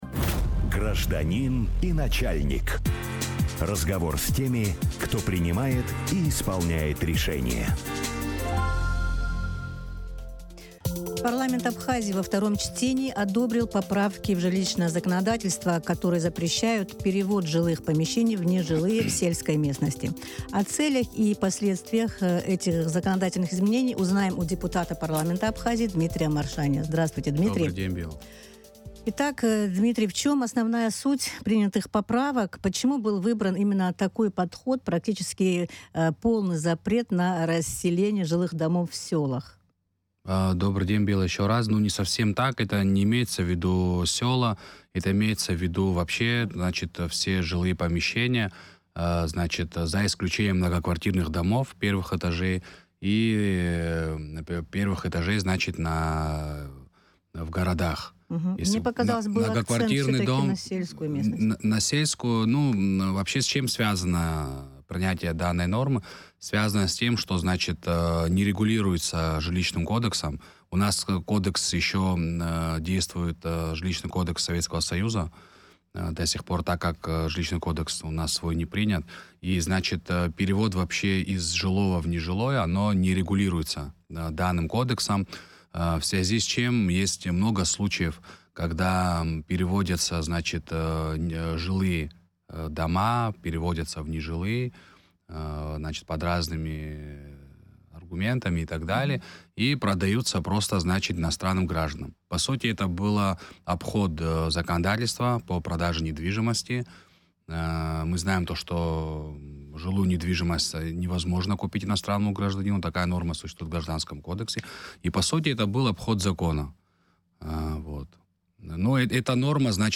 Жилье, бюджет и другие решения Парламента: интервью с депутатом